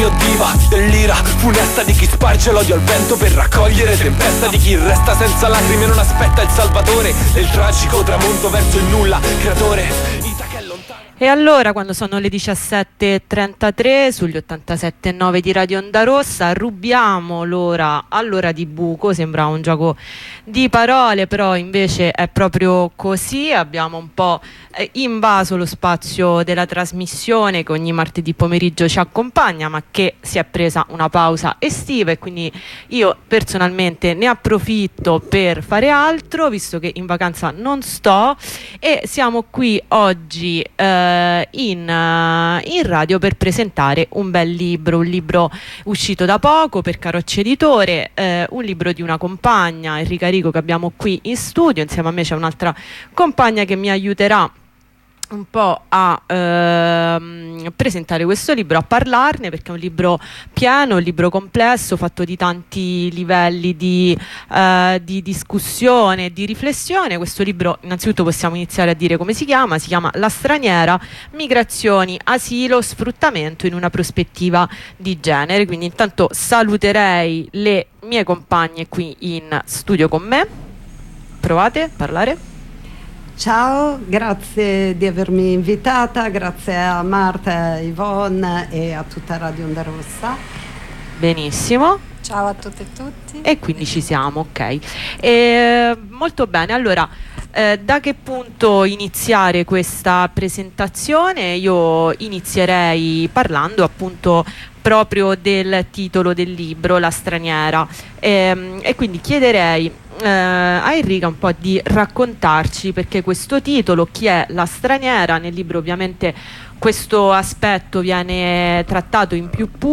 Redazionale con una compagna